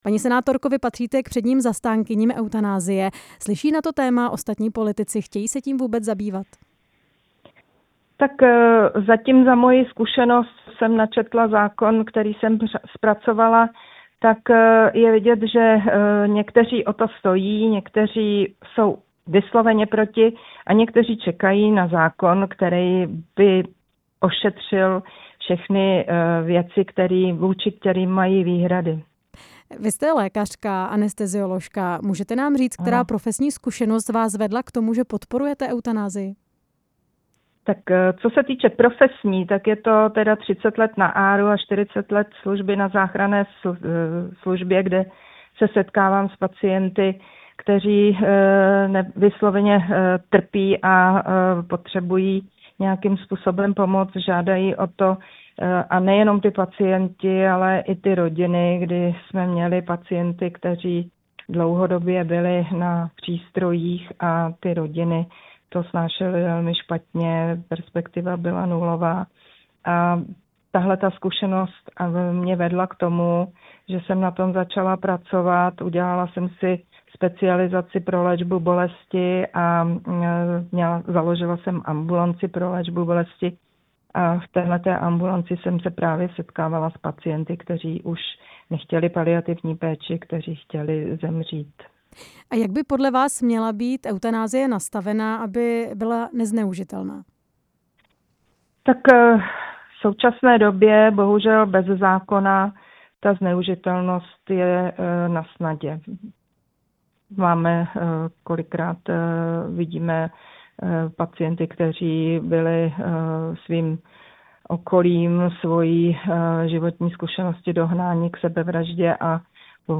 Teď bude řeč o paliativní péči a eutanazii či asistované sebevraždě. Jednat se o nich bude v Senátu na konferenci, na jejíž organizaci se podílí senátorka Věra Procházková z hnutí ANO, která byla hostem ve vysílání Radia Prostor.
Rozhovor se senátorkou Věrou Procházkovou